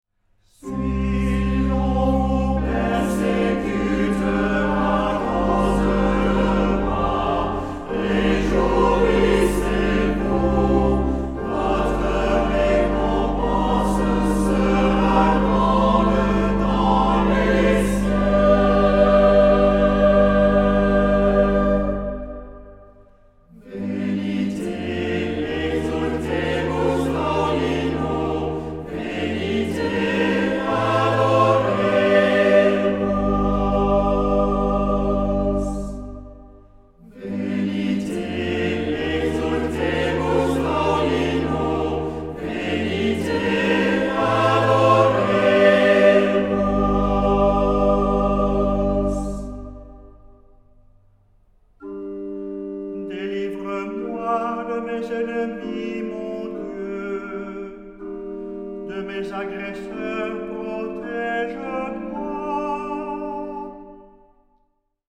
Genre-Style-Form: Sacred ; troparium ; Psalm
Mood of the piece: collected
Type of Choir: SATB  (4 mixed voices )
Instruments: Organ (1)
Tonality: G tonal center